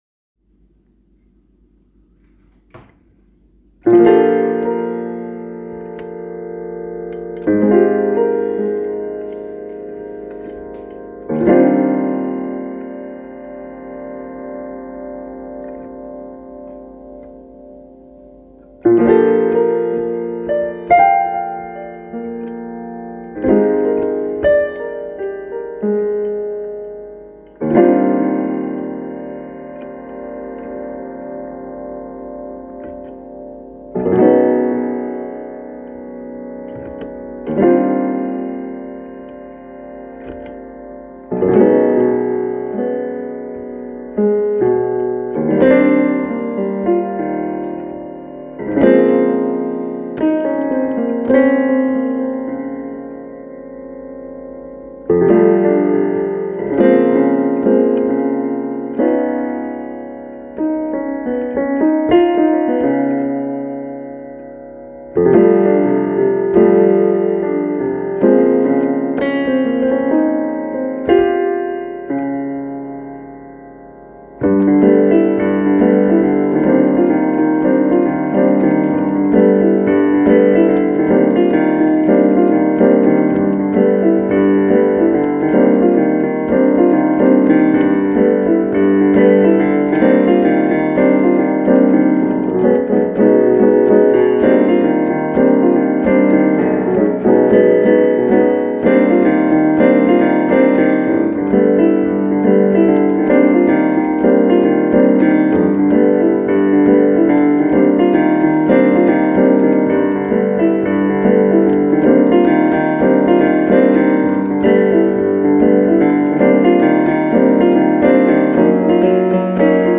Hey everyone, this is my first post on the forum, and I thought I'd just ask your opinions of this semi-improv piece I wrote on my keyboard. Sorry if the recording quality (or my quality as a pianist) is lacking.
I feel like you create some really interesting harmonic progressions and some ostinati that bring lots of energy and drive but then at the midpoint of the piece it sounds like all that kind of fizzles out.
This was mostly just an amassed collection of riffs I had tried to connect together using improvisation.
Lovely harmonies!